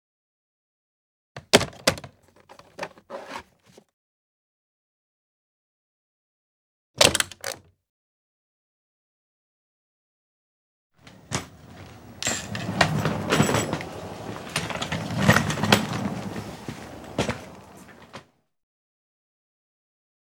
household
Case Luggage Noise 2